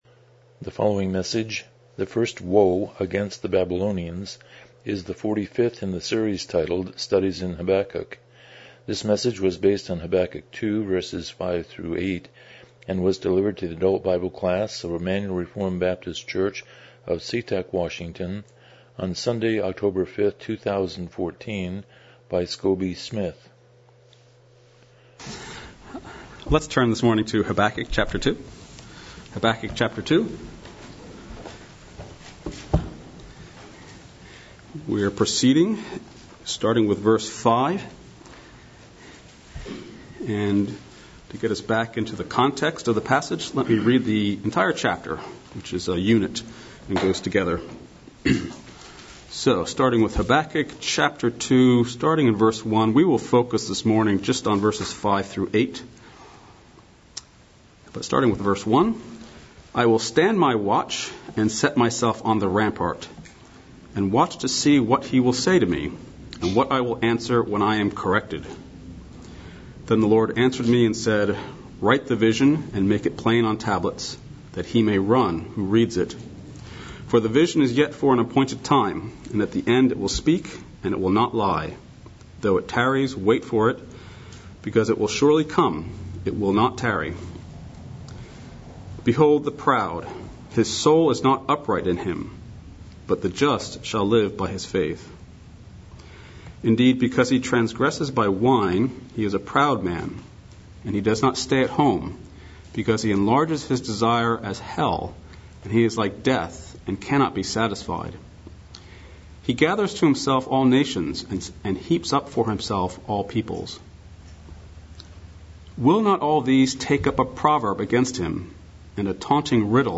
Passage: Habakkuk 2:5-8 Service Type: Sunday School